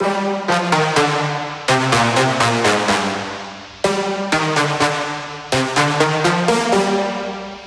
Index of /99Sounds Music Loops/Instrument Loops/Brasses